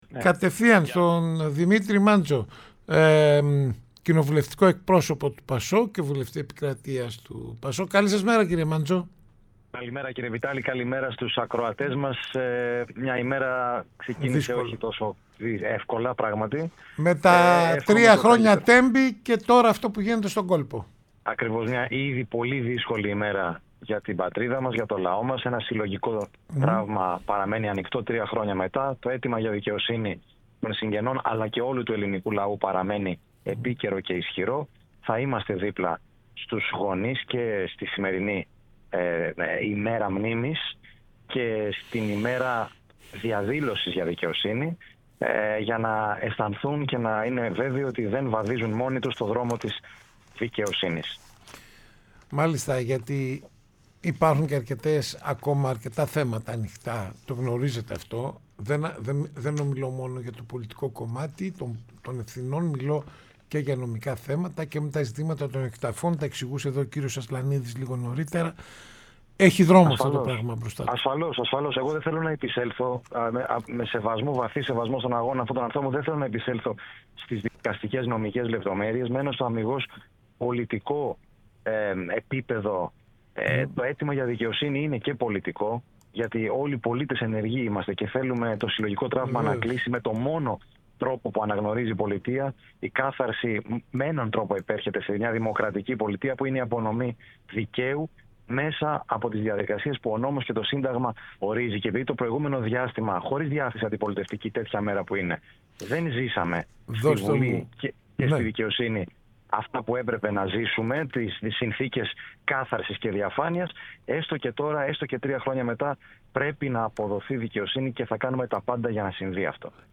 Δημήτρης Μάντζος, Κοινοβουλευτικός Εκπρόσωπος του ΠΑΣΟΚ-ΚΙΝΑΛ, μίλησε στην εκπομπή Στο Ρυθμό Της Επικαιρότητας